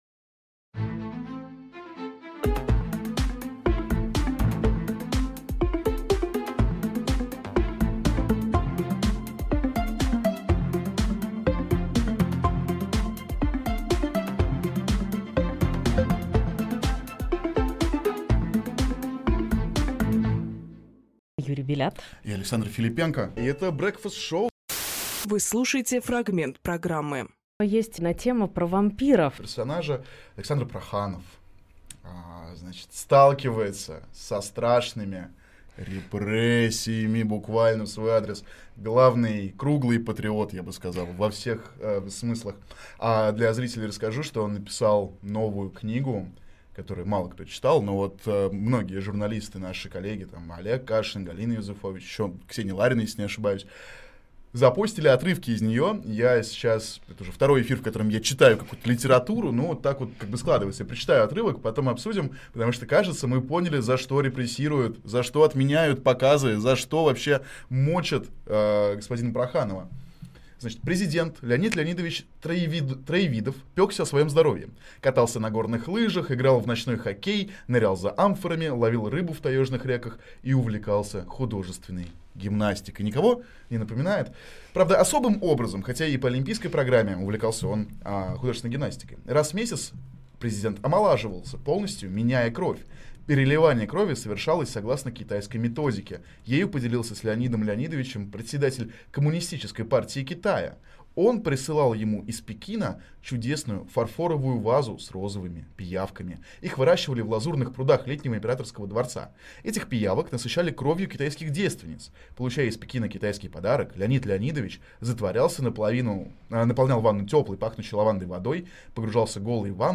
Фрагмент эфира от 05.10.25